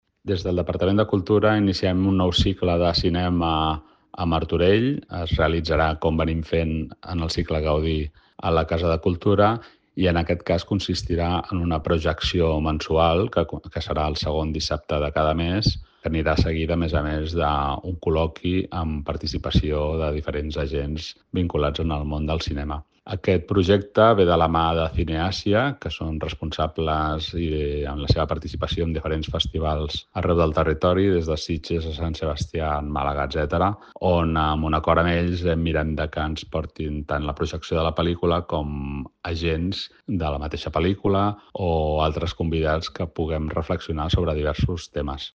Sergi Corral, regidor de Cultura